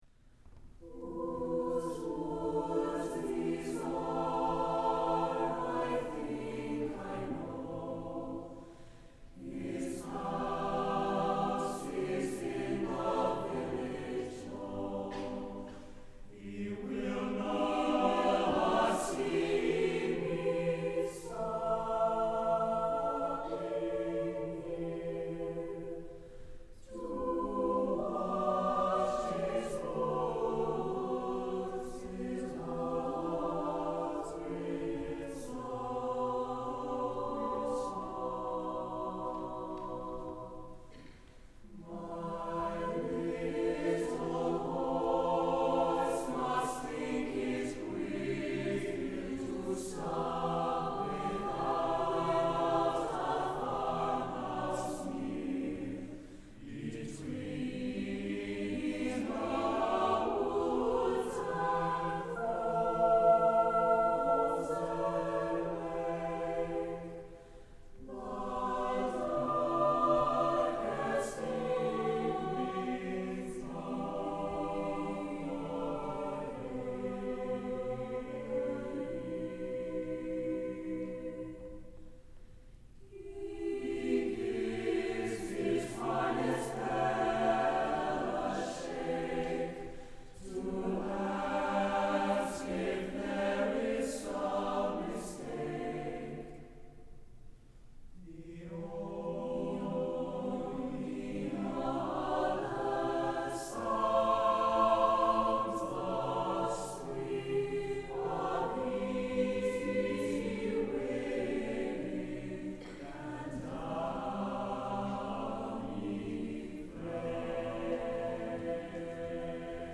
for unaccompanied mixed chorus